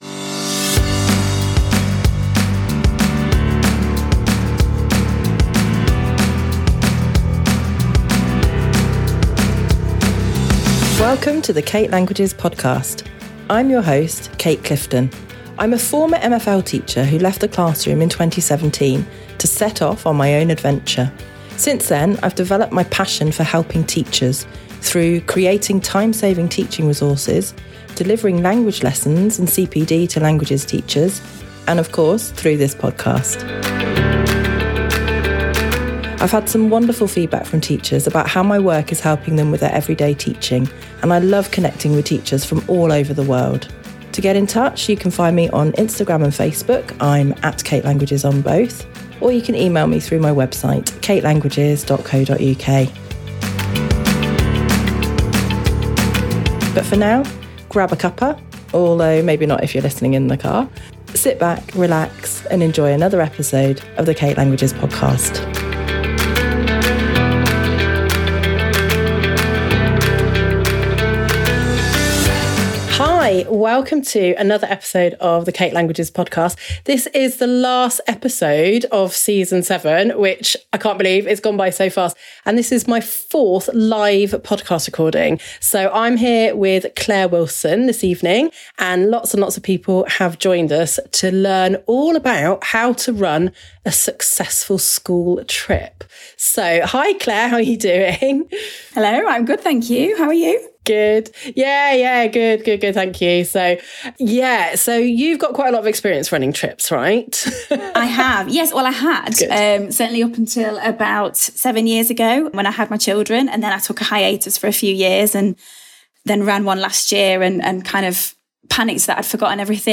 This was my 4th live podcast recording and is absolutely packed to the brim with fantastic advice about running a school trip abroad!
During the recording, we answered lots of great questions from the audience - have a listen to find out whether your question was included!